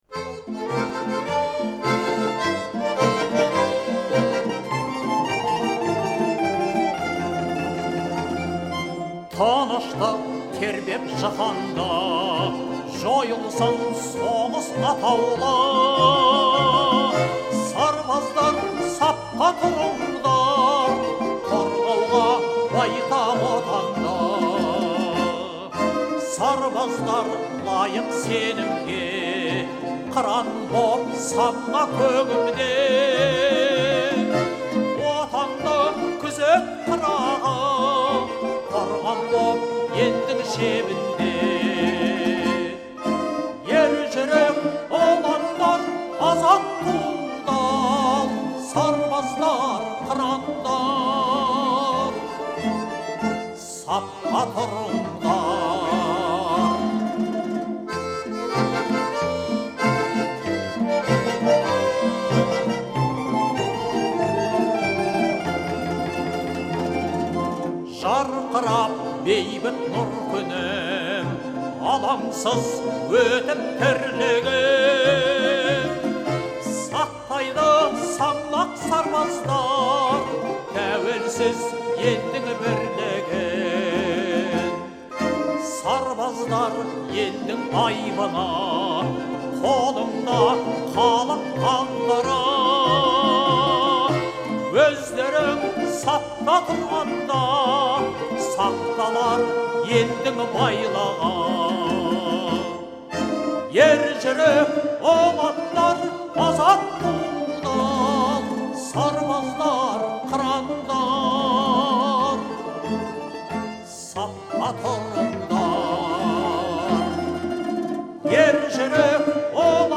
это патриотическая песня
которая относится к жанру марша.